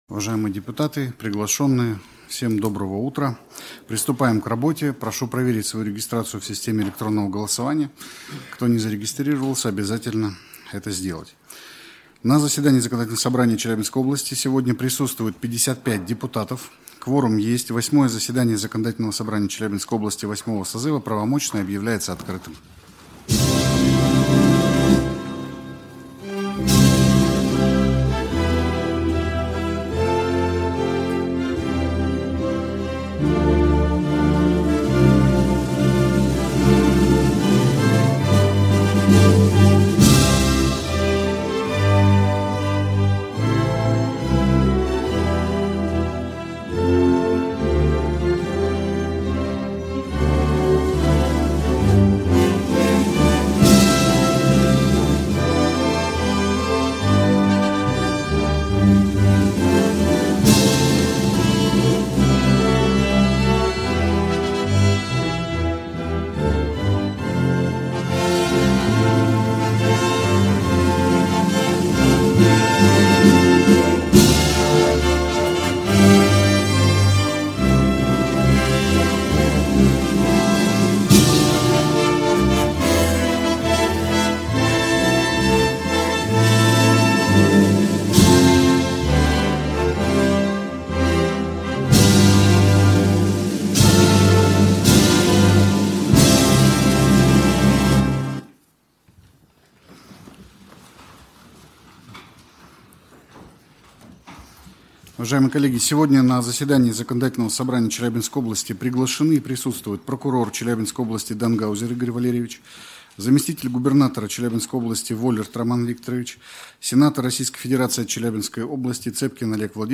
Восьмое заседание Законодательного Собрания Челябинской области VIII созыва